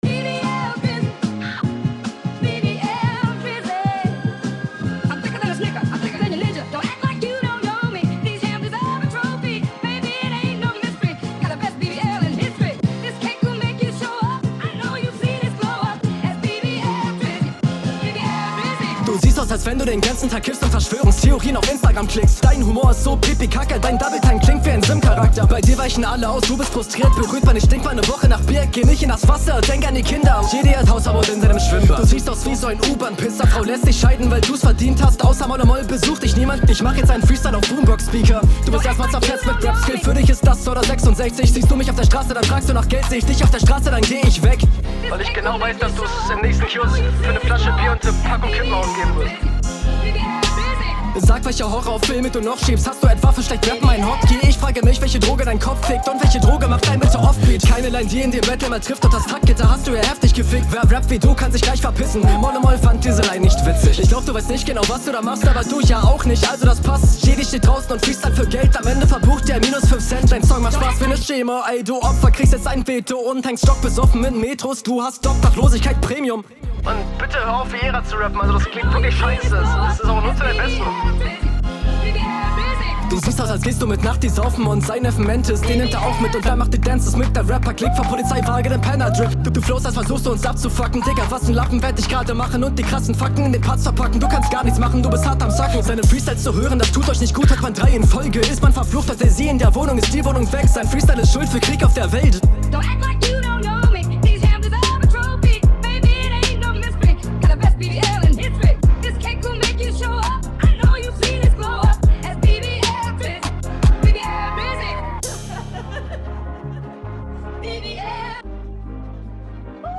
viele random lines, sehr doper flow auf nem dopen beat, gegnerbezug auch viel da
Flow kommt richtig gut auf dem Beat.